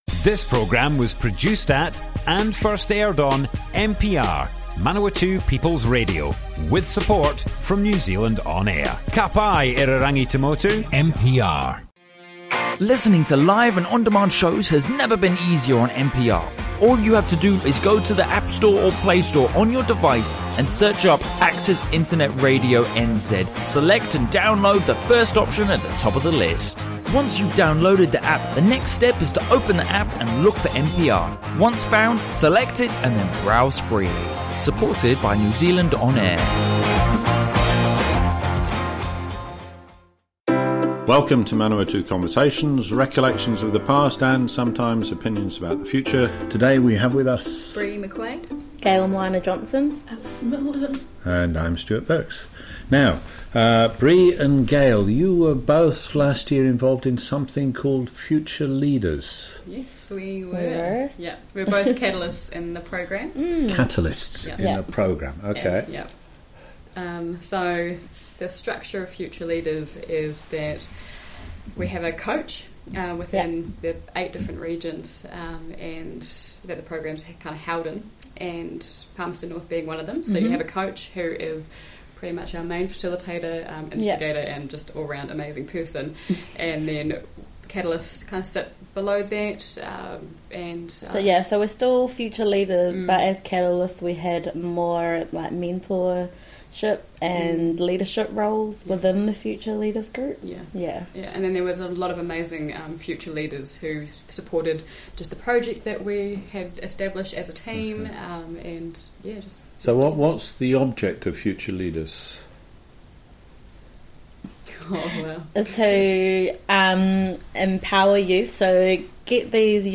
Broadcast on Manawatu People's Radio 25 June, 2019.